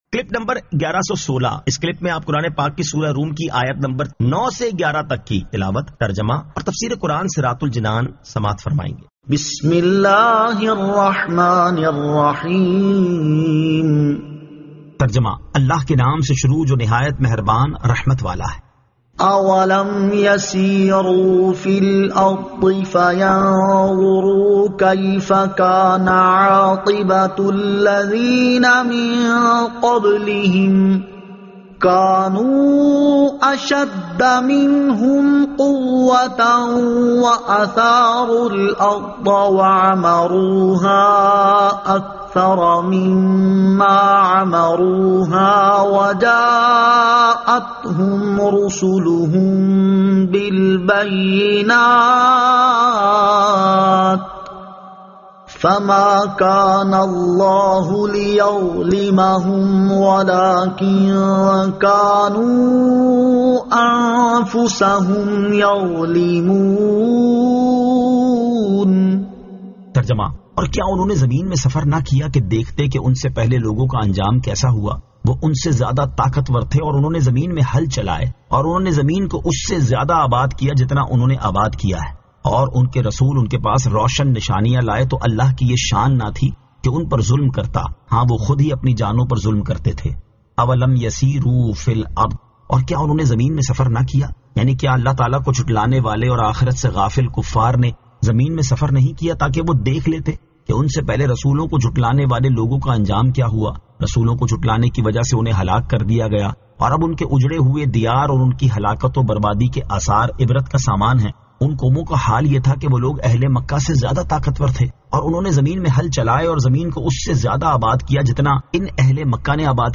Surah Ar-Rum 09 To 11 Tilawat , Tarjama , Tafseer